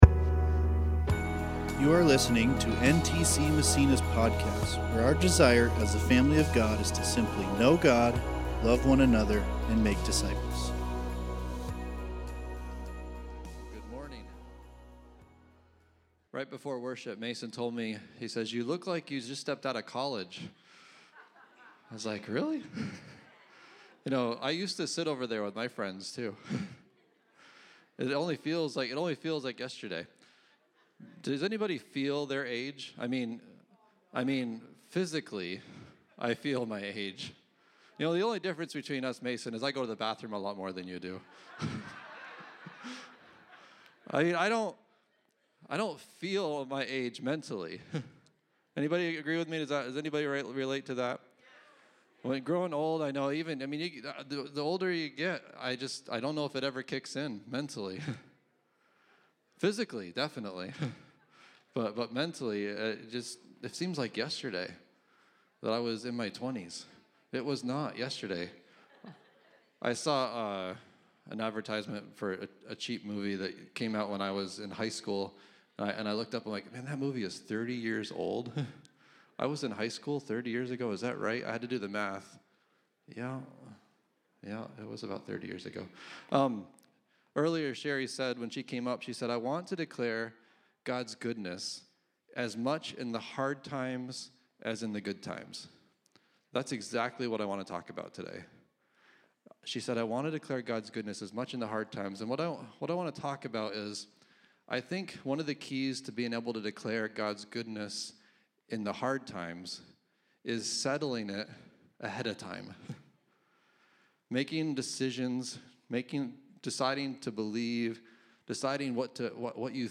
2024 Growing W.6- Grow Up Preacher